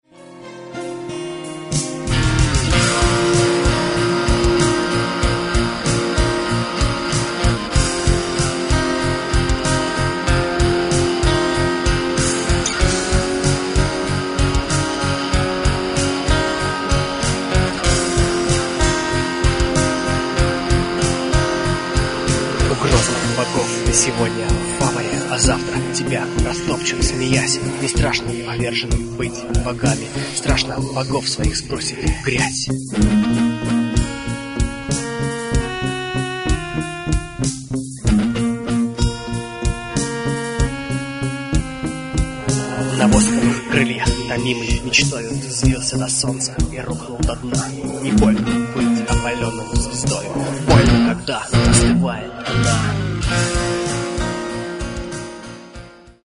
Клавиши, гитары, перкуссия, вокал
фрагмент (330 k) - mono, 48 kbps, 44 kHz